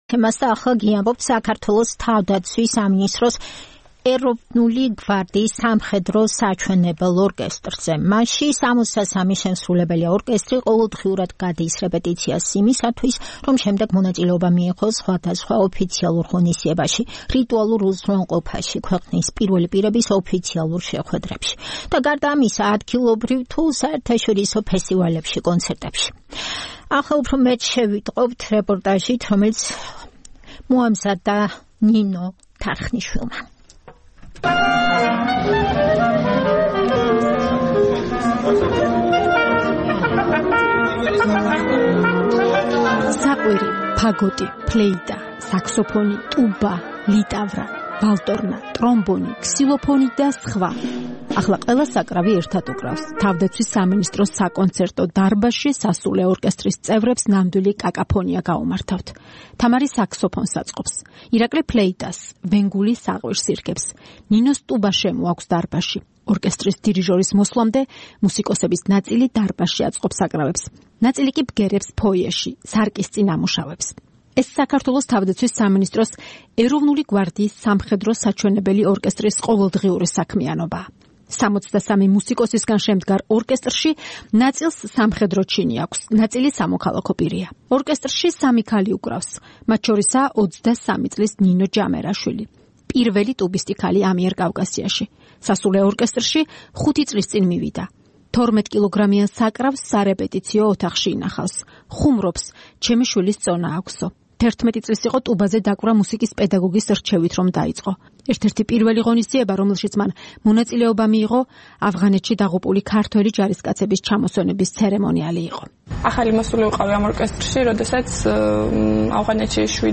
სასულე ორკესტრის მრავალი ფერი
საქართველოს თავდაცვის სამინისტროს ეროვნული გვარდიის სამხედრო საჩვენებელ ორკესტრში სამოცდასამი შემსრულებელია. ორკესტრი ყოველდღიურად გადის რეპეტიციას იმისათვის, რომ შემდეგ მონაწილეობა მიიღოს სხვადასხვა ოფიციალურ ღონისძიებაში, რიტუალურ უზრუნველყოფაში, ქვეყნის პირველი პირების ოფიციალურ შეხვედრებში და, გარდა ამისა, ადგილობრივ თუ საერთაშორისო ფესტივალებსა და კონცერტებში.